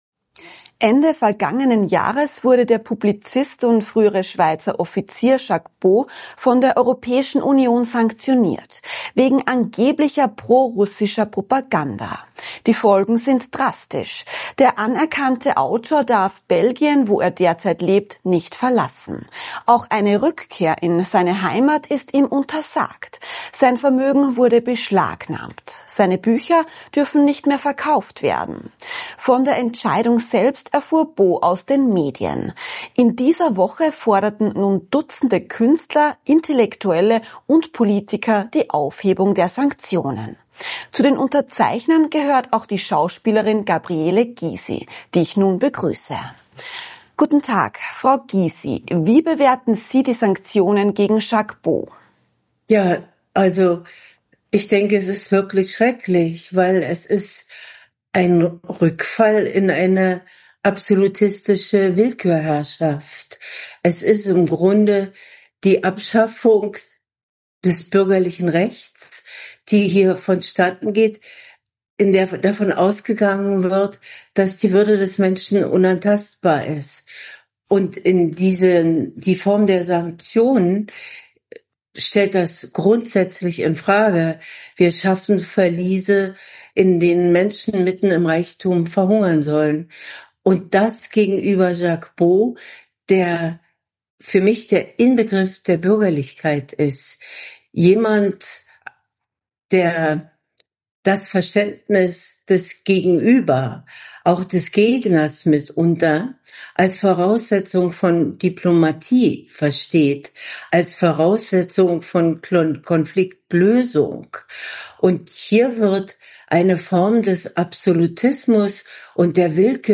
Im Exklusiv-Interview mit AUF1 nimmt sie kein Blatt vor den Mund. Die Strafmaßnahmen aus Brüssel bedeuteten das Ende bürgerlicher Rechte – und die Rückkehr von Absolutismus und Willkürherrschaft.